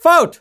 umpire_m_fault03.mp3